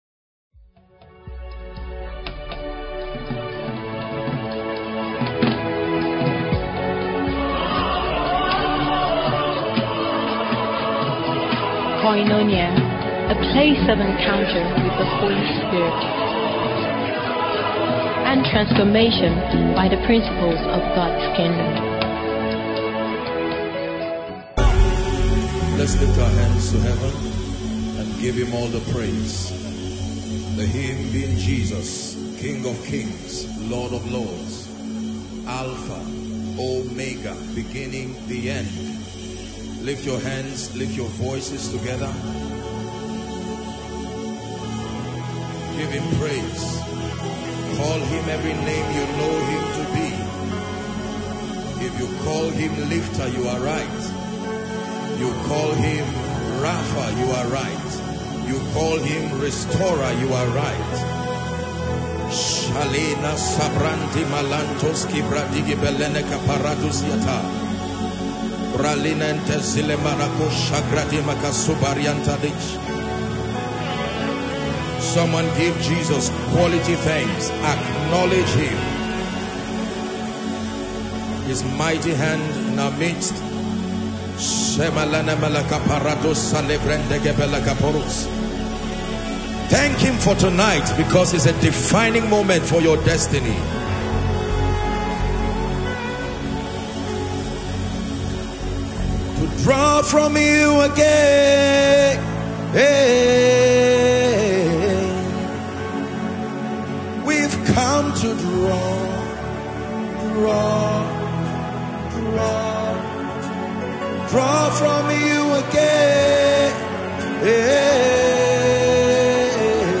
Held in a charged atmosphere of faith and expectation, thousands gathered to experience the miraculous move of God, as lives were transformed through healing, deliverance, and the prophetic.
The service began with an intense session of worship and praise, ushering in the tangible presence of God. The choir led the congregation in powerful songs that prepared hearts for an outpouring of the supernatural.